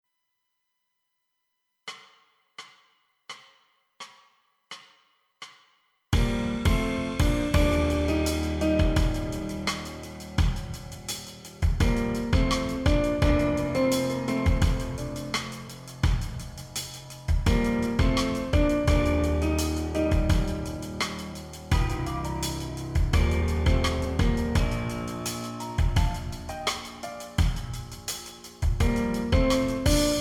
This is an instrumental backing track cover.
• Key – B♭
• Without Backing Vocals
• No Fade